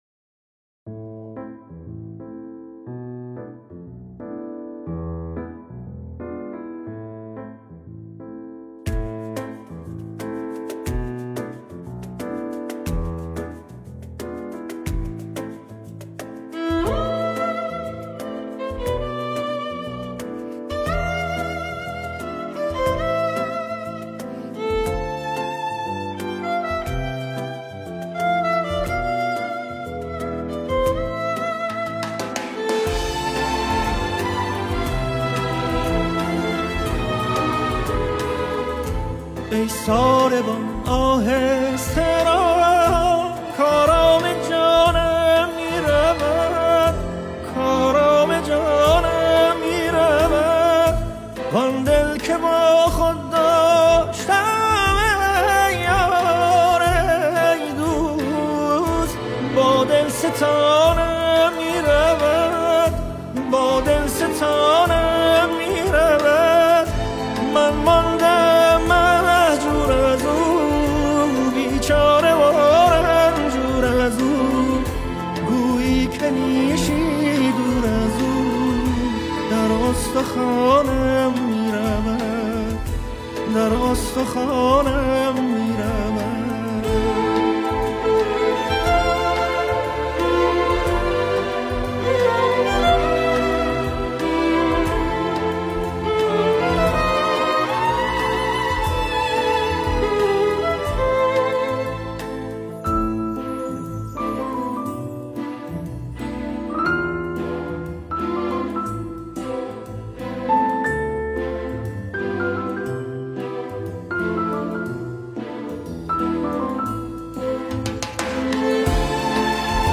ویولا، ویولن
ویلنسل
سازهای کوبه‌ای
پیانو